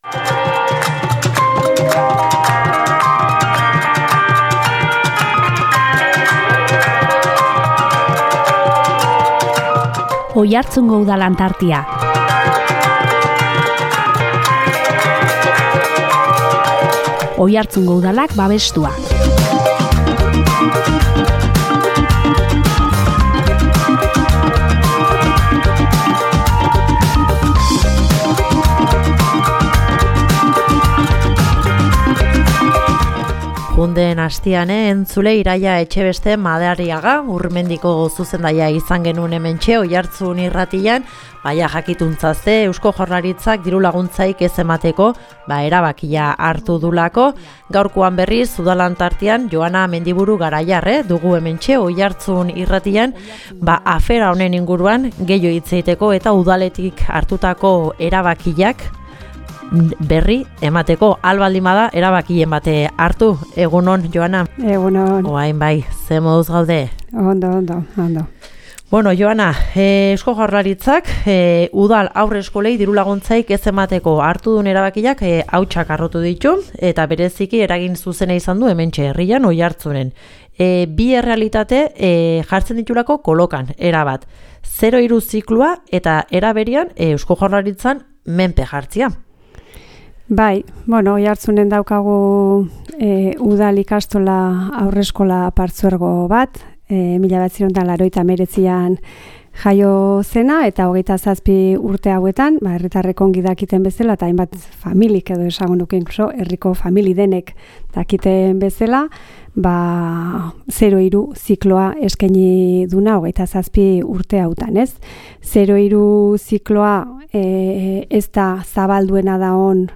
Joana Mendiburu Garaiar alkateak proposamenak izan duen harreraren berri eman digu Oiartzun Irratian.